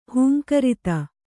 ♪ hūmkarita